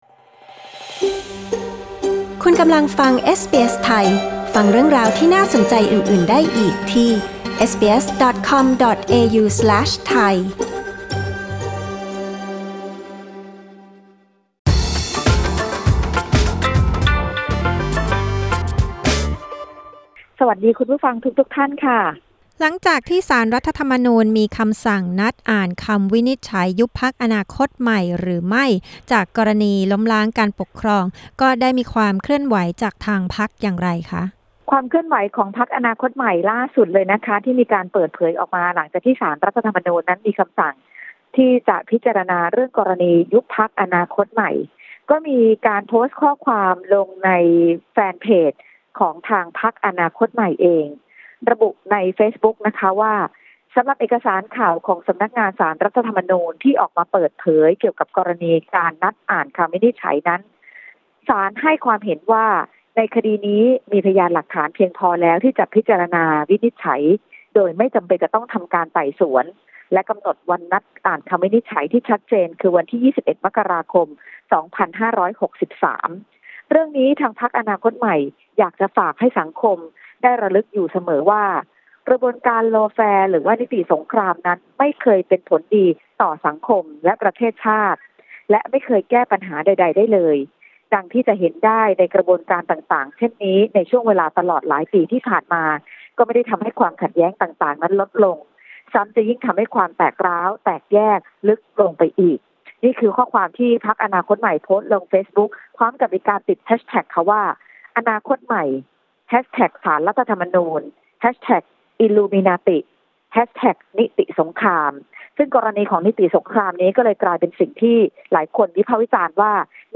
กดปุ่ม 🔊 ด้านบนเพื่อฟังรายงานข่าว